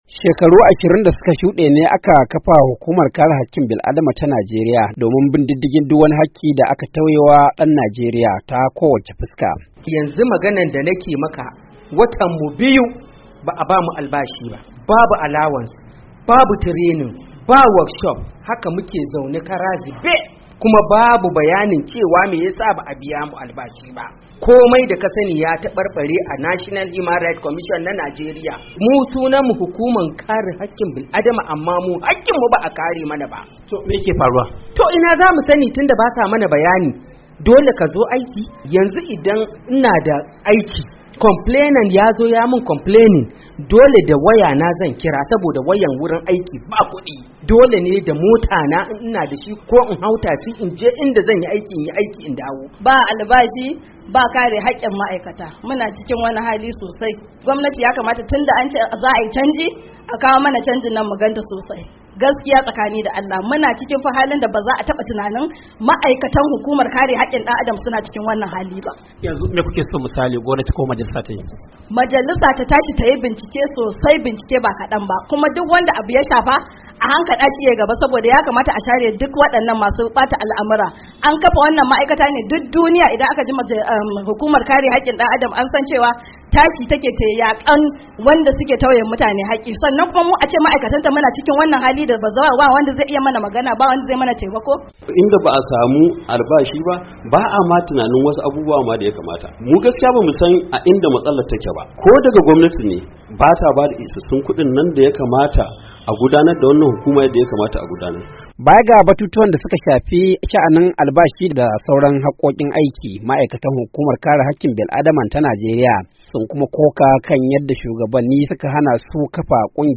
Ga cikakken rahoto